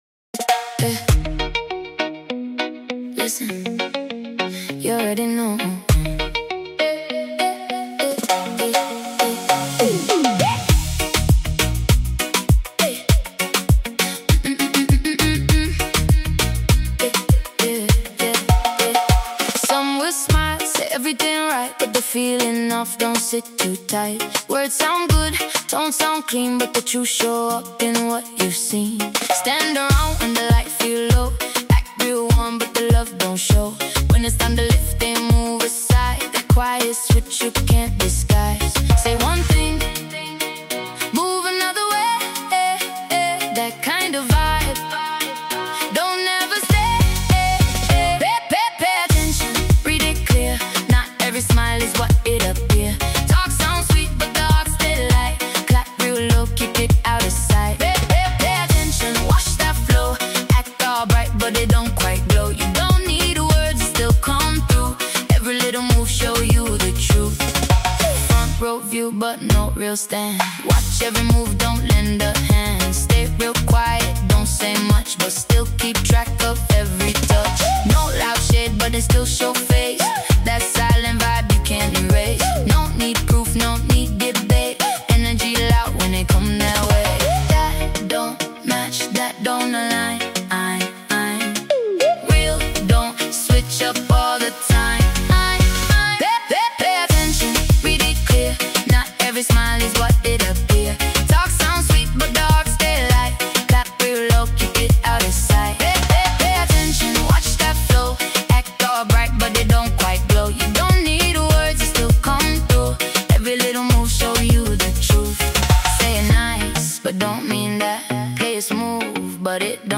As it plays, the tone becomes more intentional.